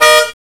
CARHORN.WAV